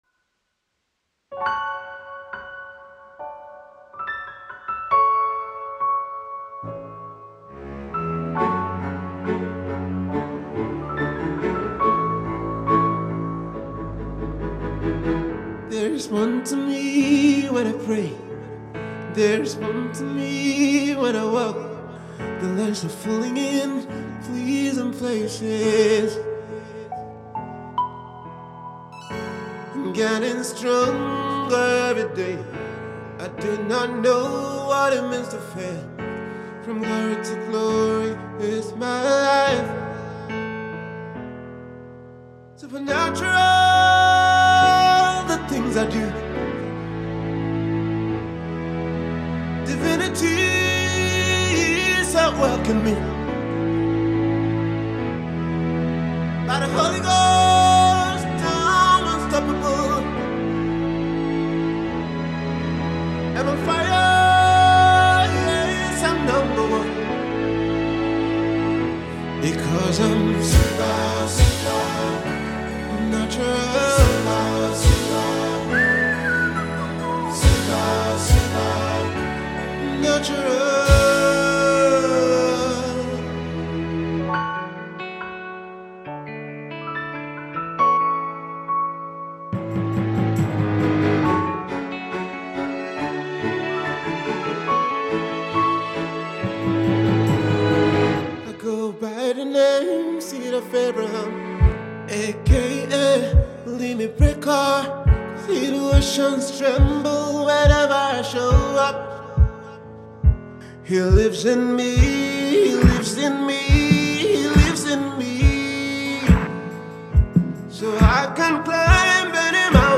gospel artiste